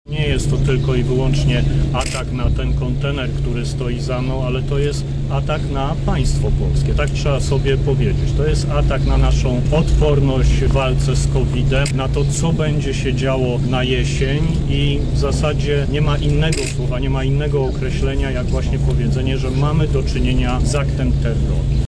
Nie mam słów. Widziałem obrazki, które mrożą krew w żyłach – mówi minister zdrowia Adam Niedzielski: